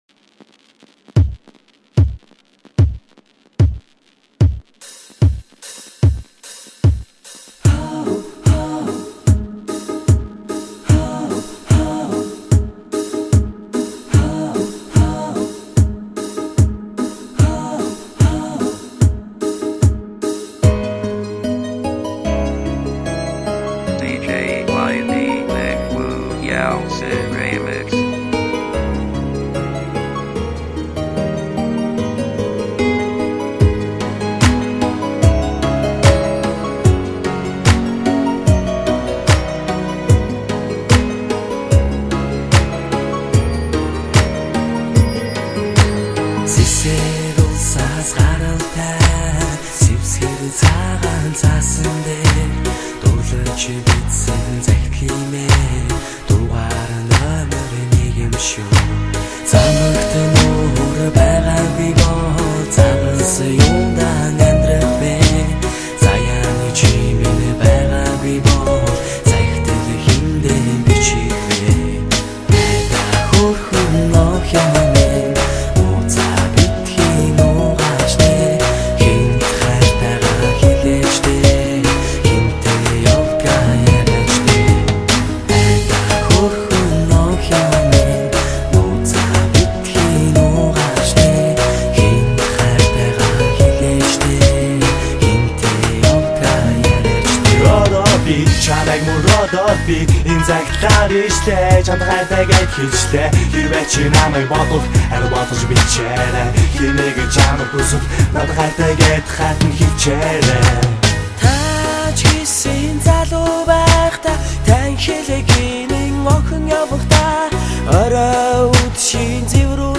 Pop / Rap